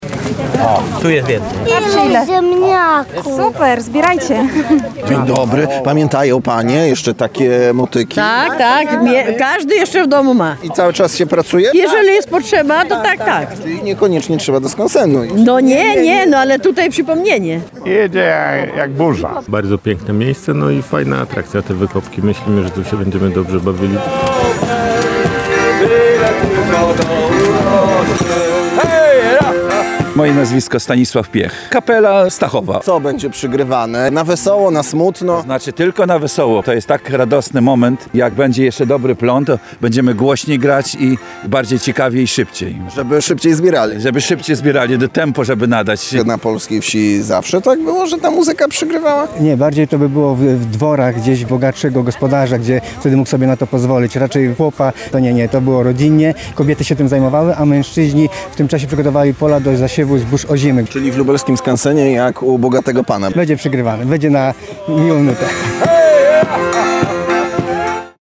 Tradycyjną motyką lub konno - w Muzeum Wsi Lubelskiej zorganizowano wykopki kartoflane.
POSŁUCHAJ RELACJI DŹWIĘKOWEJ:
– Bardzo piękne miejsce i fajna atrakcja – mówi w rozmowie z Radiem Lublin jeden z uczestników wydarzenia.